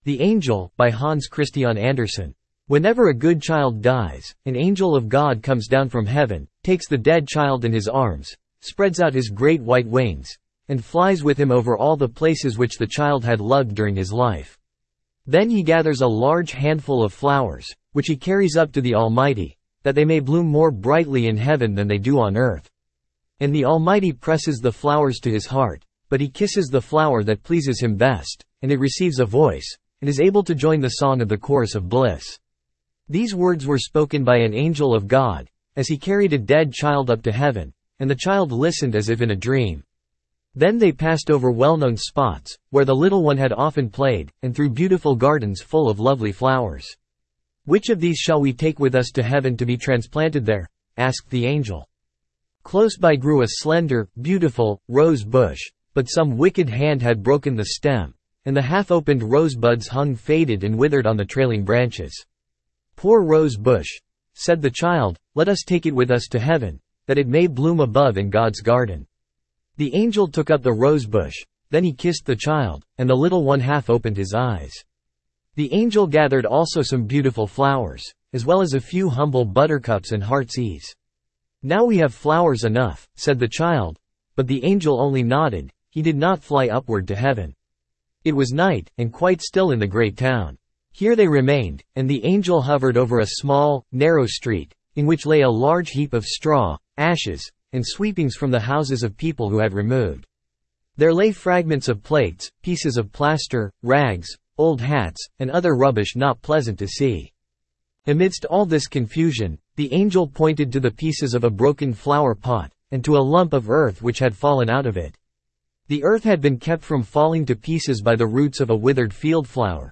Standard (Male)